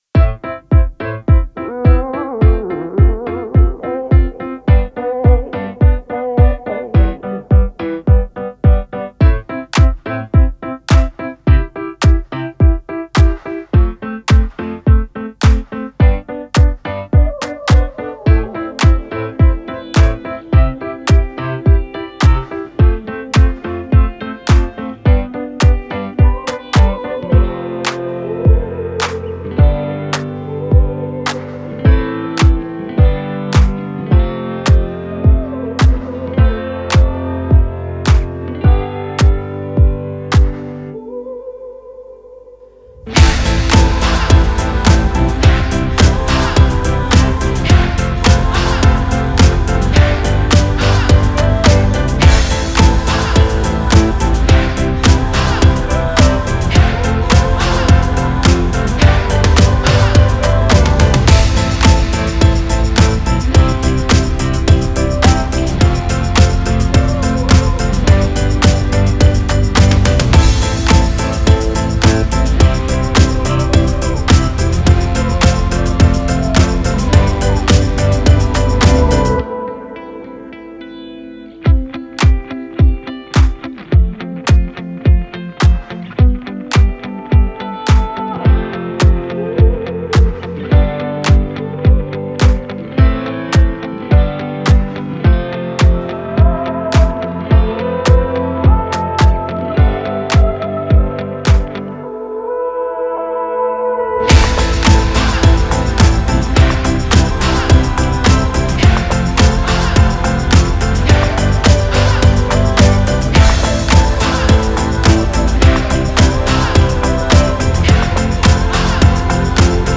MUSICA INSTRUMENTAL (sem voz)